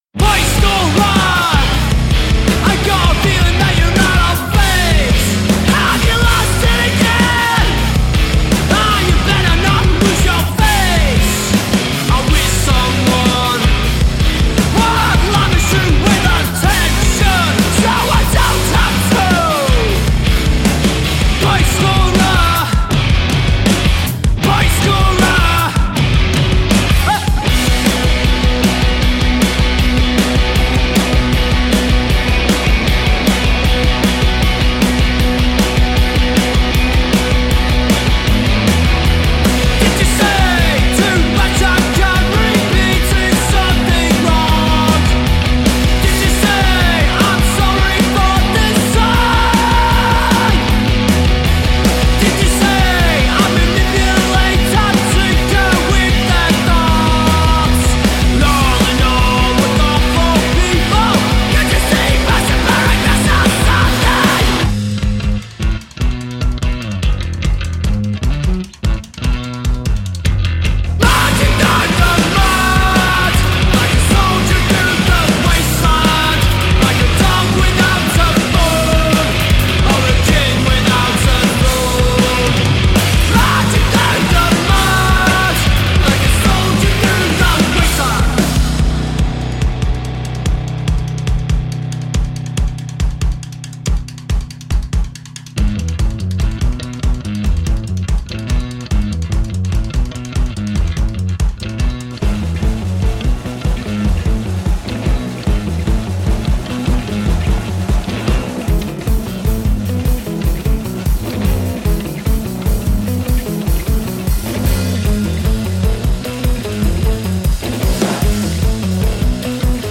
alternative rock band